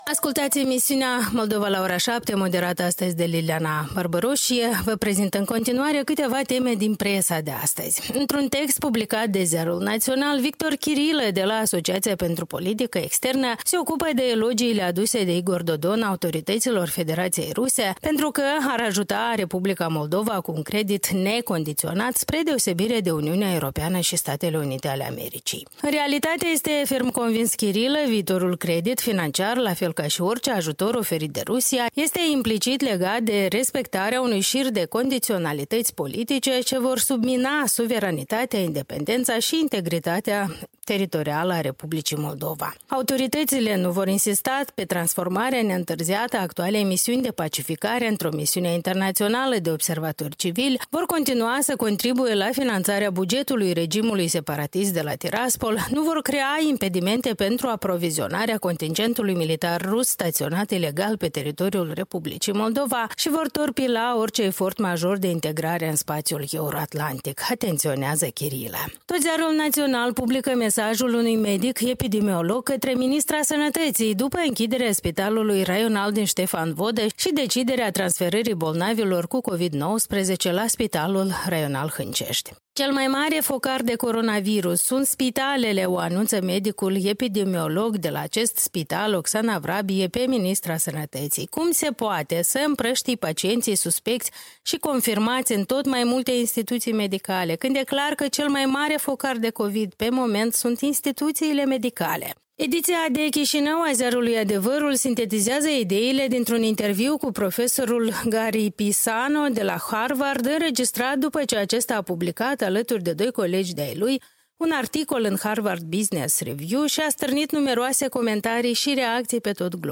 Revista presei din R.Moldova, luni, 6 aprilie 2020, la radio Europa Liberă.